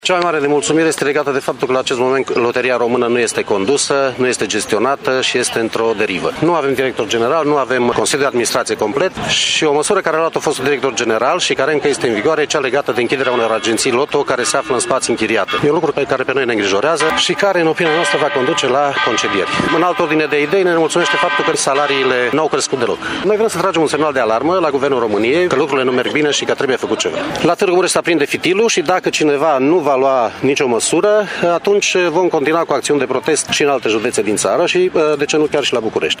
Circa 30 de sindicaliști care lucrează în agențiile LOTO din municipiu au pichetat sediul Prefecturii Mureș, nemulțumiți de situația gravă în care se află compania.